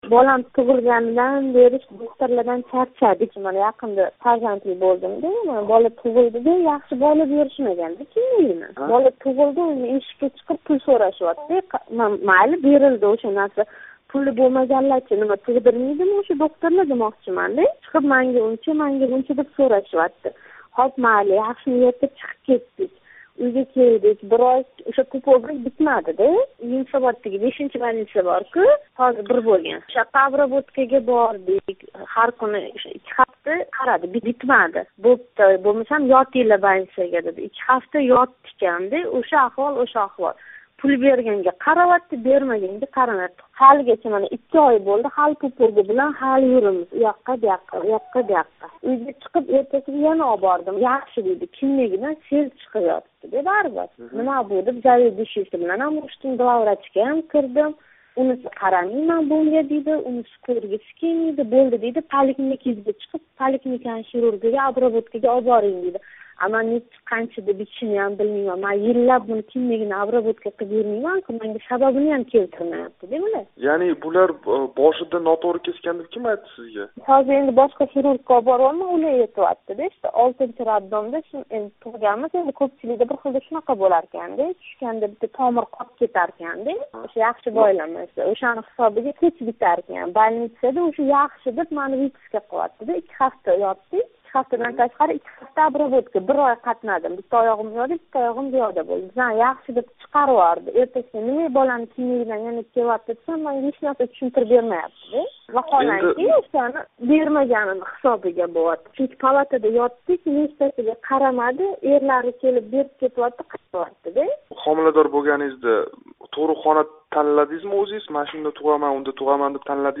Тошкентлик она билан суҳбатни қуйида тўлиқ тинглашингиз мумкин: